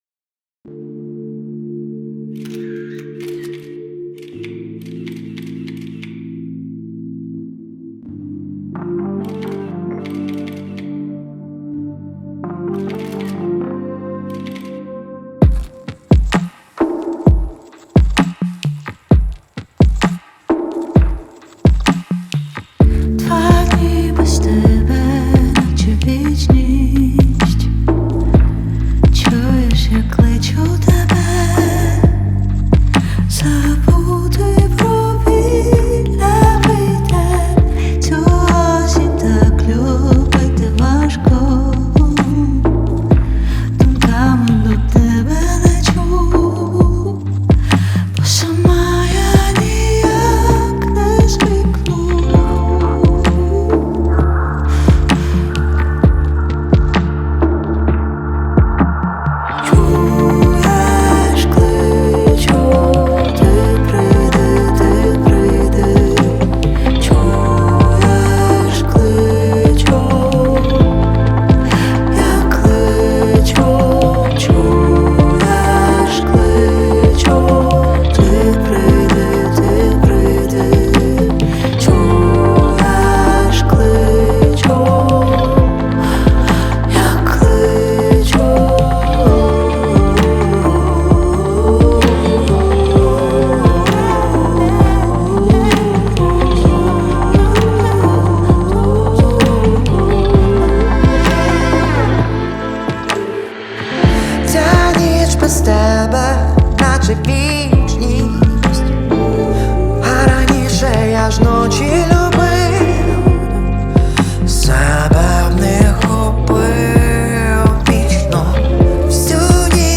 Жанр: Узбекские песни